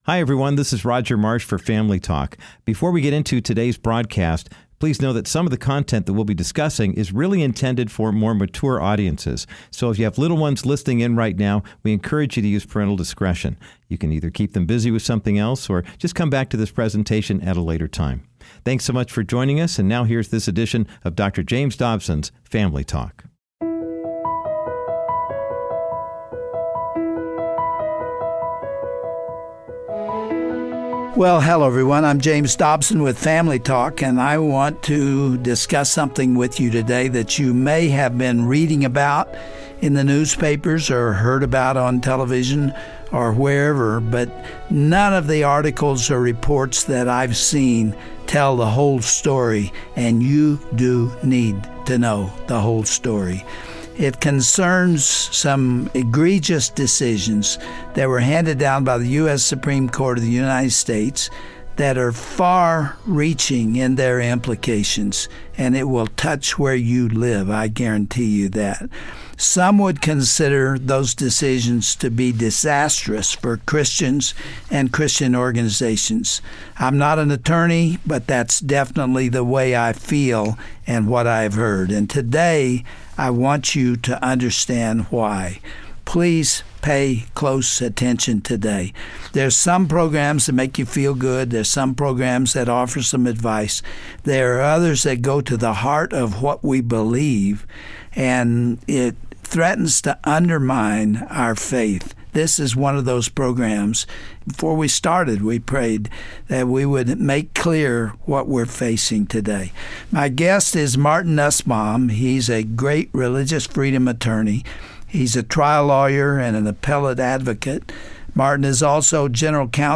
A few weeks ago, The U.S. Supreme Court controversially expanded discrimination laws to include those in the LGBTQ community. On this important Family Talk broadcast